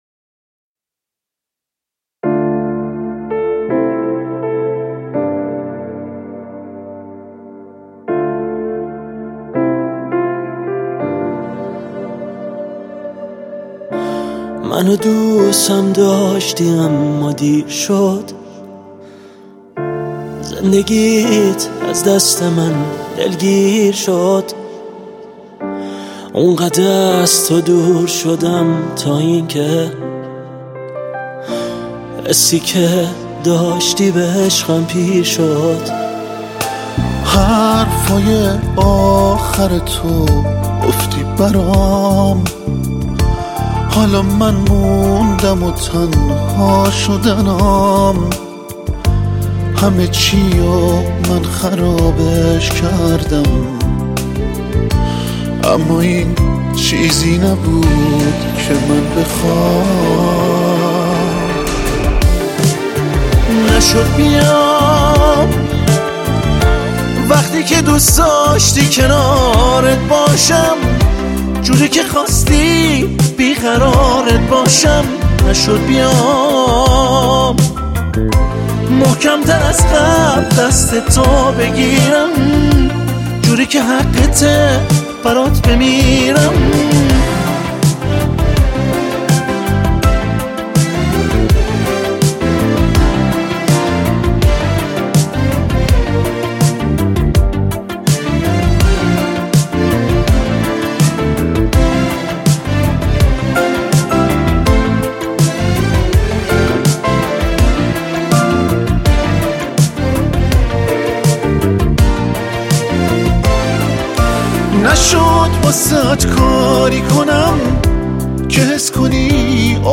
غمگین
عاشقانه و غمگین